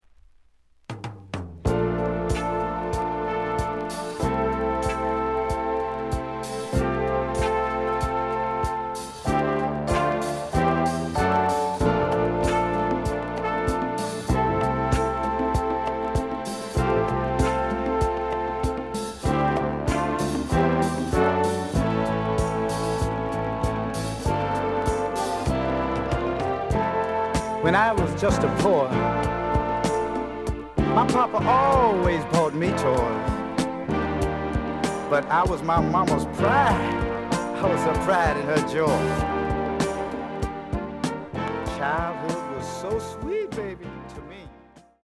The audio sample is recorded from the actual item.
●Format: 7 inch
●Genre: Soul, 70's Soul